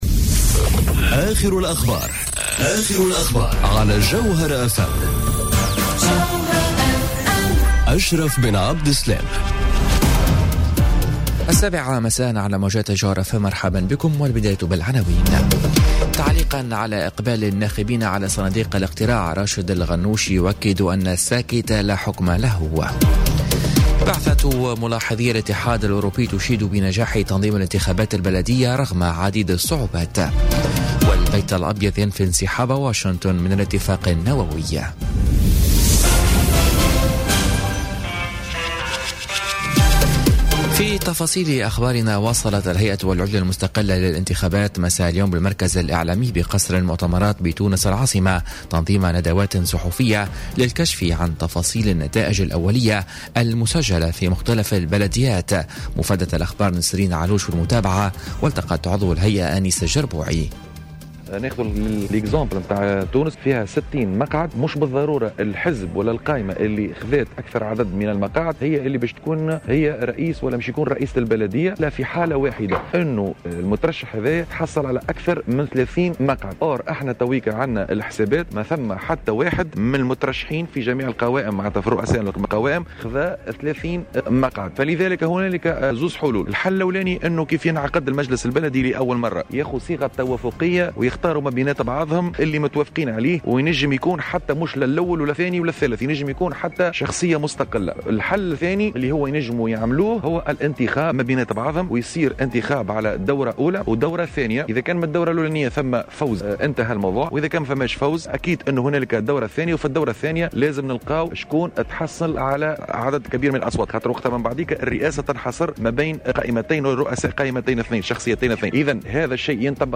نشرة أخبار السابعة مساءً ليوم الثلاثاء 8 ماي 2018